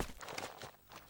sounds / material / human / step / earth1.ogg
earth1.ogg